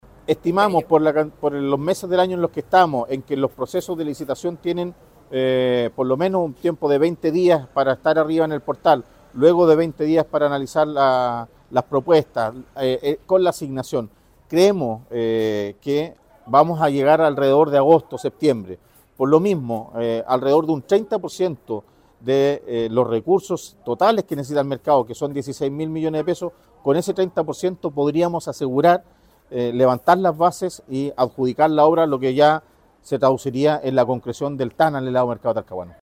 Además, la autoridad local se refirió a los tiempos estimados en caso de que se realice la obra este año, señalando que solo se necesitaría el 30% del presupuesto total ($16 mil millones) para iniciar.
Mercado-Talcahuano-3-Alcalde-Talcahuano.mp3